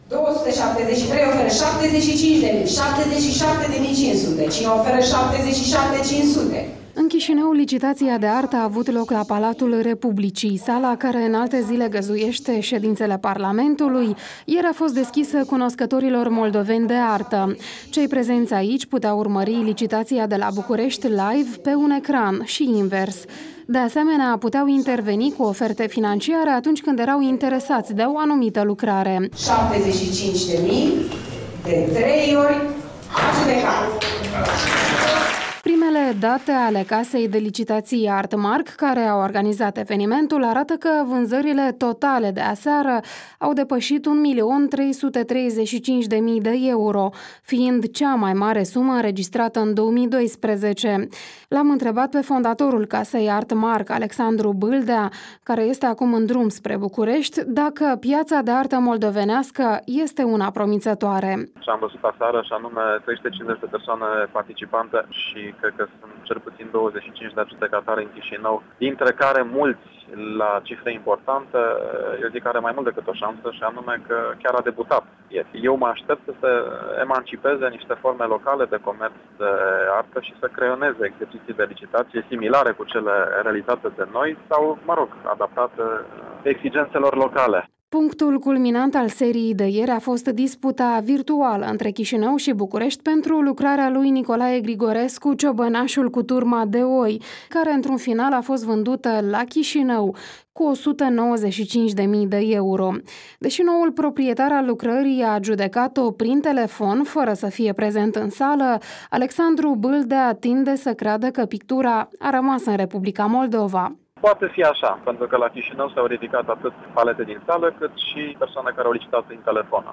Un reportaj de la prima licitaţie internaţională de artă Bucureşti-Chişinău